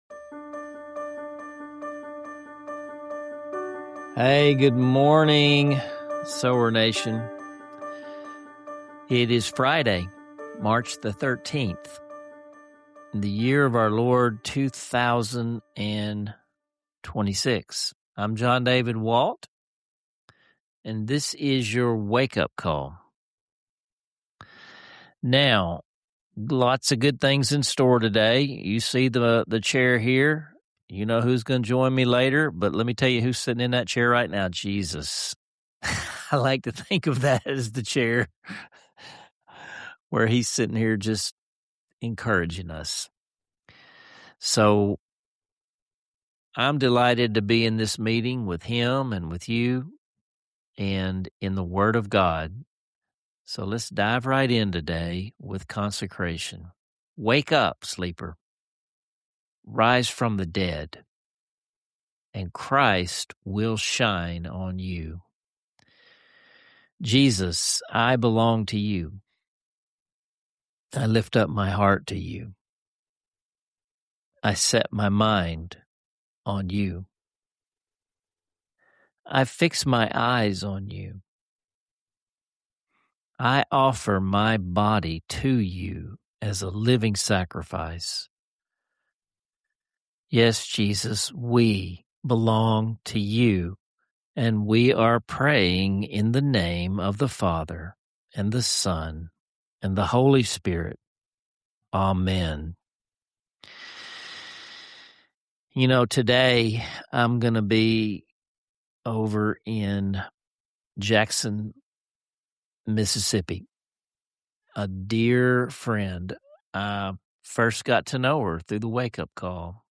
A timeless hymn performed together—inviting listeners into their own “sweet hour of prayer”
Don’t miss this inspiring conversation—subscribe now for more uplifting episodes that help you awaken to God’s love and purpose in your everyday life!